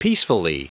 Prononciation du mot peacefully en anglais (fichier audio)
Prononciation du mot : peacefully